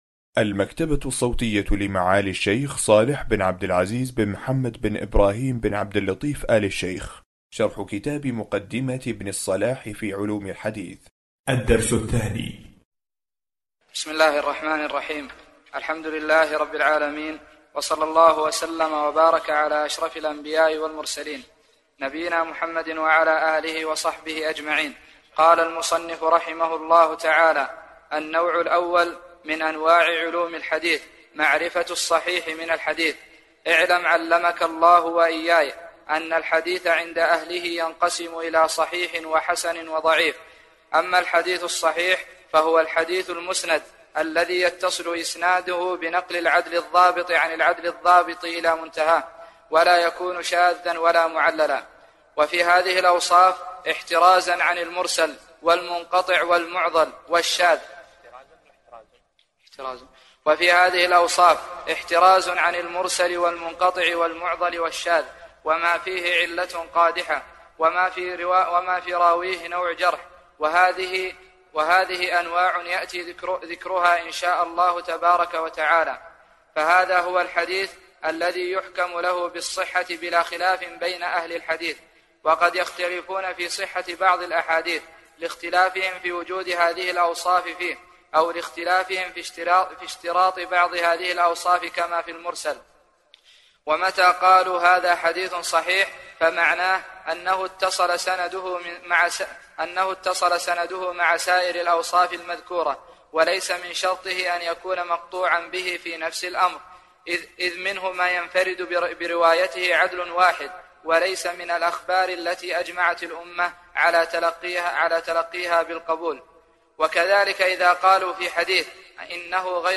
معرفة أنواع علم الحديث (مقدمة ابن الصلاح) شرح الشيخ صالح بن عبد العزيز آل الشيخ الدرس 2